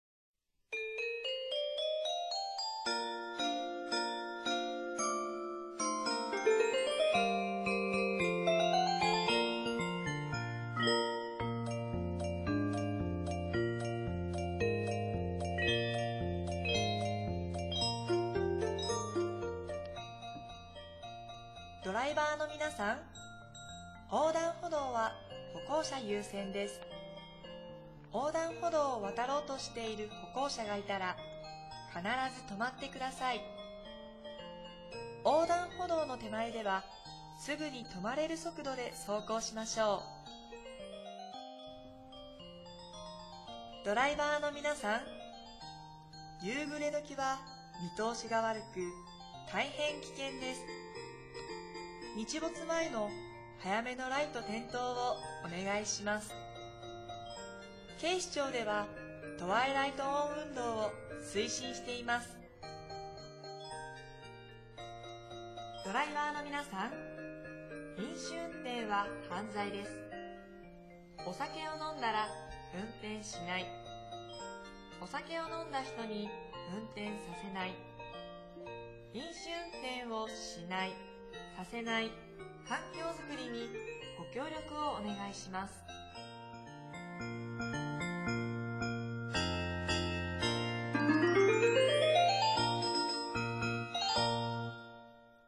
内容は、「ピーポくんのうた」オルゴールバージョンをBGMに、歩行者・自転車など、テーマ別に大切なルールやマナーを呼びかけています。